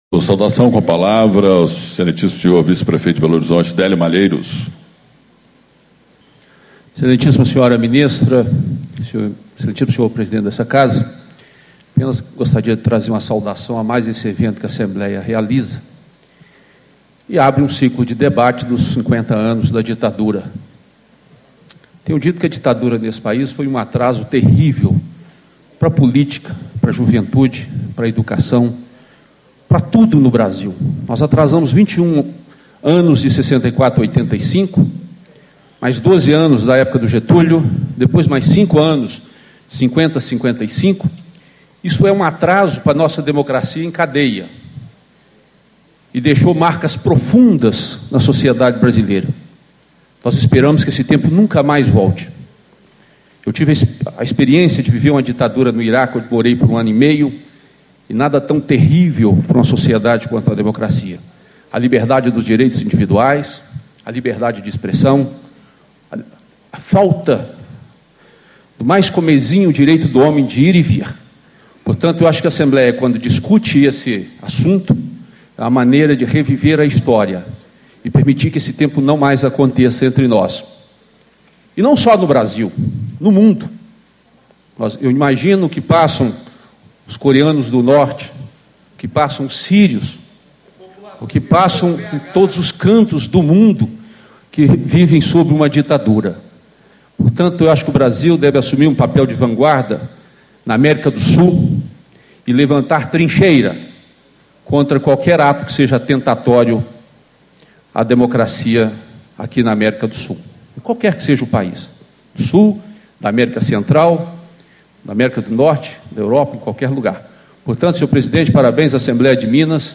Abertura - Vice-prefeito de Belo Horizonte, Délio Malheiros, PV